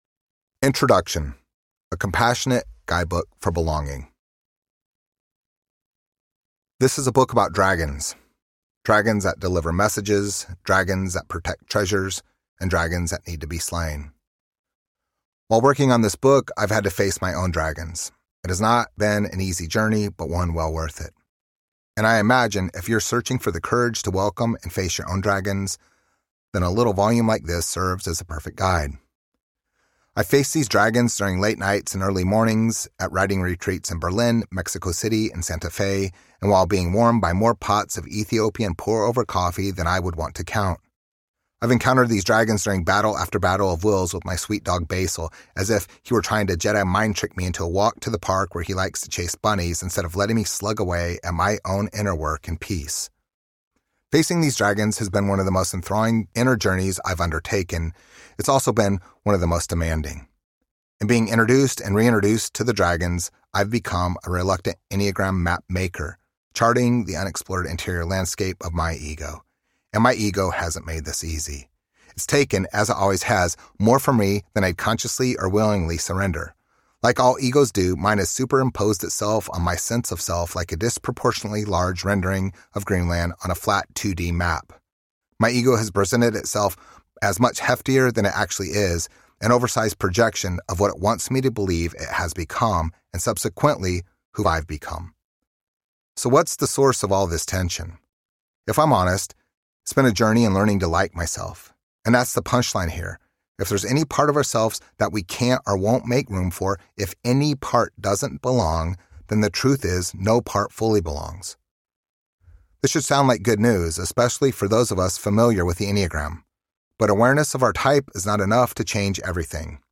The Enneagram of Belonging Audiobook
Narrator